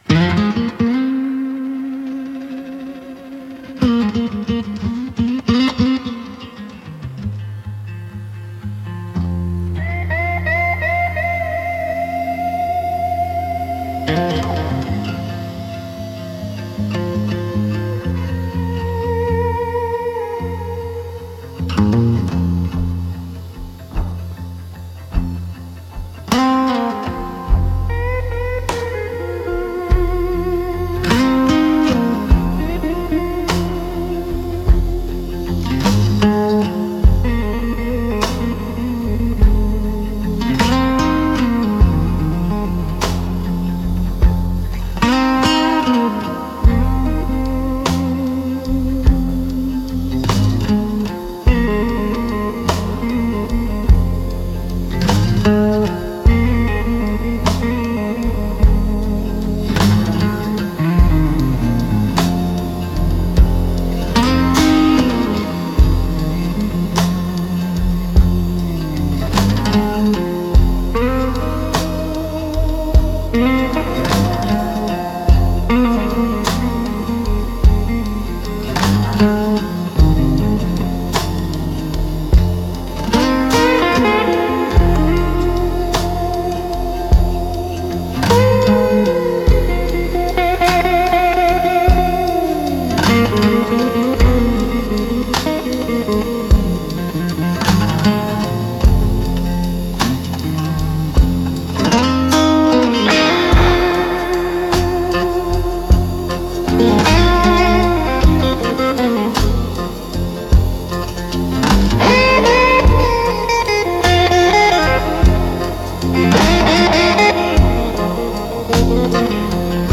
Instrumentals - Pinewood Shivers